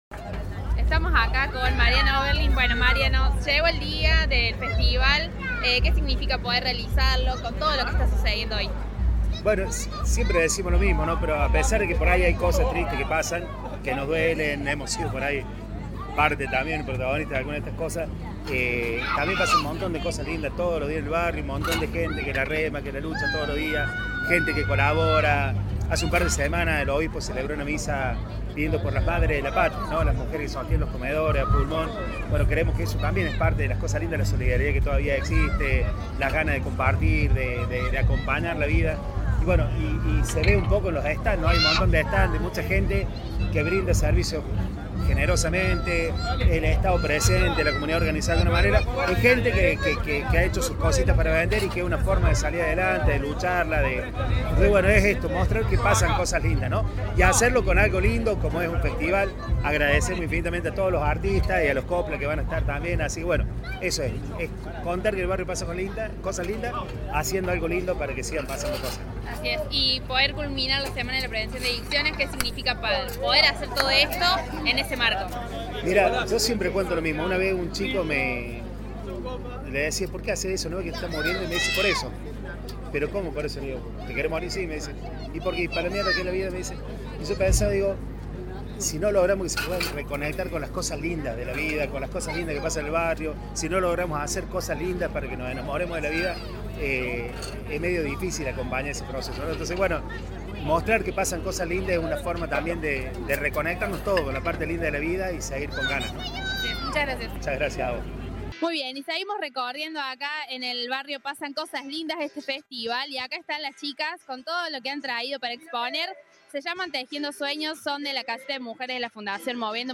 El cierre de la semana fue el festival «En el Barrio Pasan Cosas Lindas» en barrio Mûller, un evento organizado de manera mancomunada por la Municipalidad de Córdoba y el Gobierno de la Provincia, junto a la parroquia Crucifixión del Señor, ATAJO y la Fundación Moviendo Montañas.